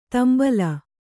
♪ tambala